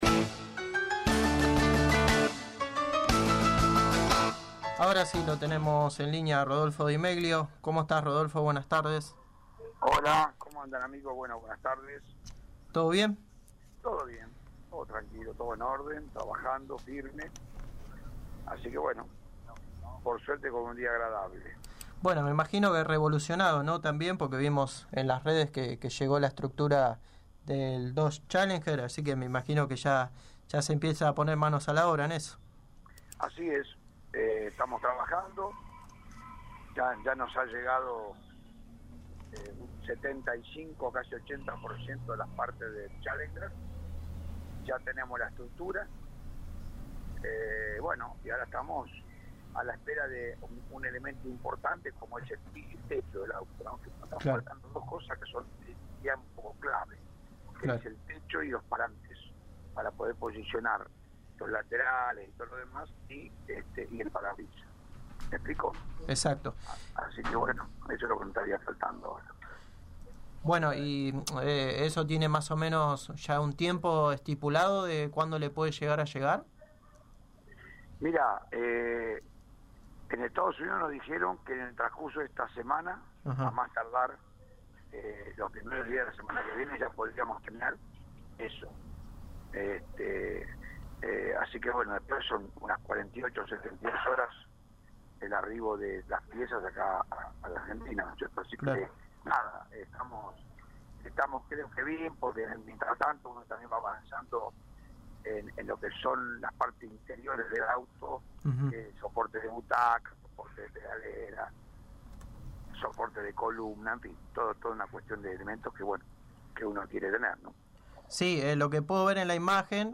pasó por los micrófonos de Pole Position y dio detalles de como se trabaja en la nueva generación del Turismo Carretera